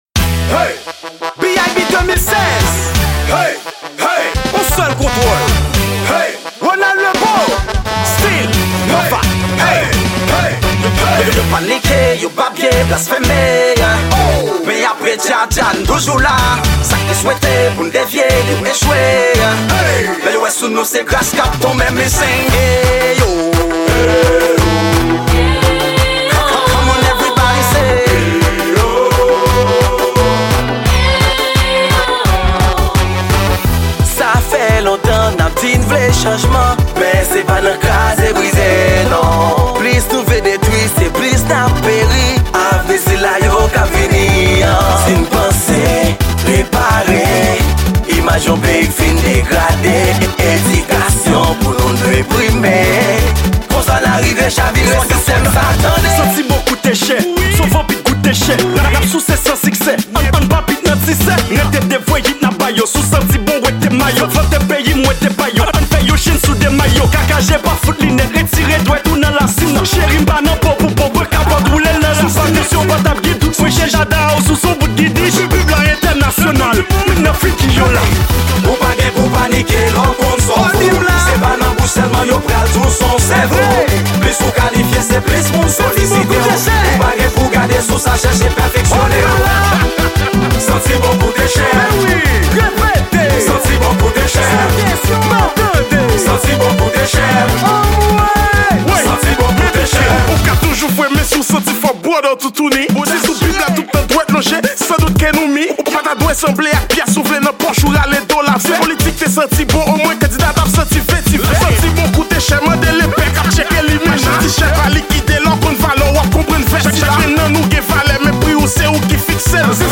Genre: Kanaval 2016.